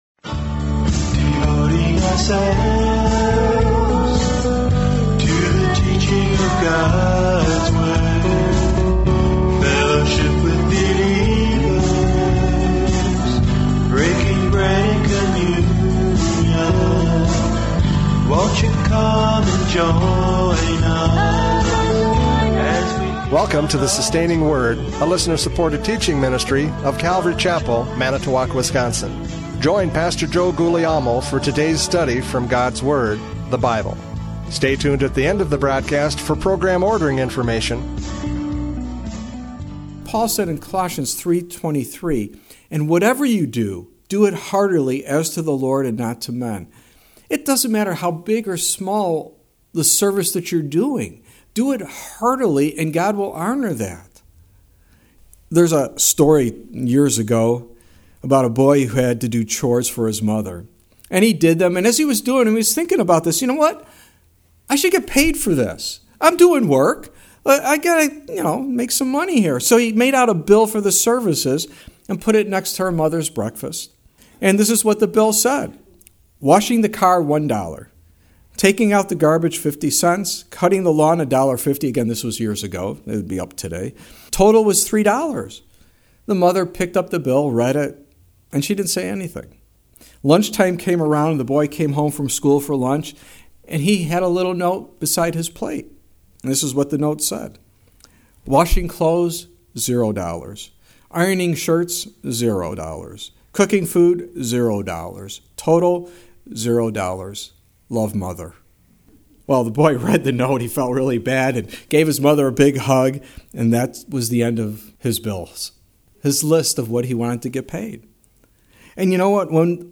John 12:1-8 Service Type: Radio Programs « John 12:1-8 The Fragrance of Worship!